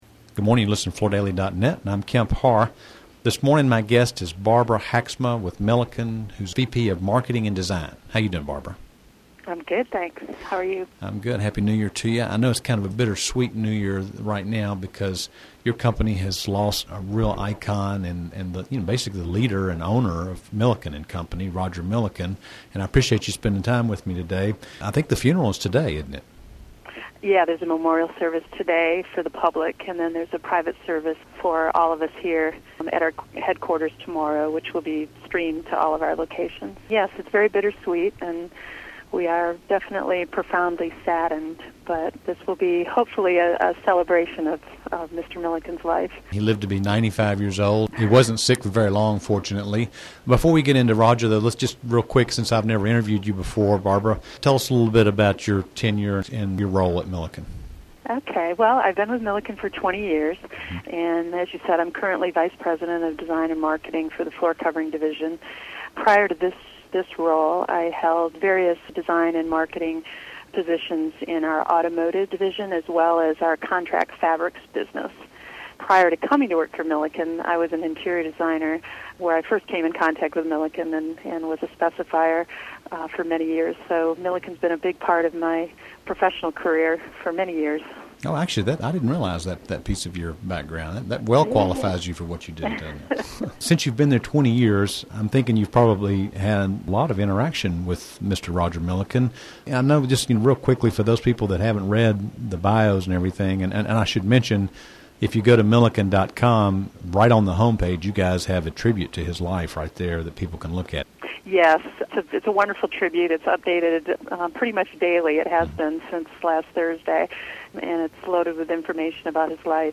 Listen to the interview to hear more about Mr. Milliken and what it was like to work alongside him--an industry icon who lived by the right principles for a company leader.